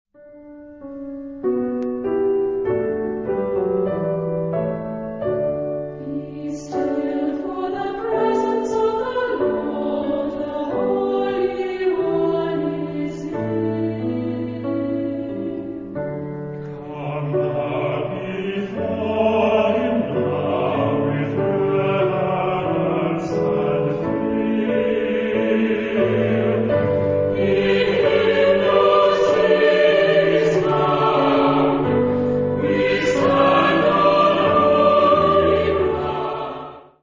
Genre-Style-Form: Motet ; Sacred ; Hymn (sacred)
Mood of the piece: expressive
Type of Choir: SAH  (3 mixed voices )
Instruments: Keyboard (1)
Tonality: D major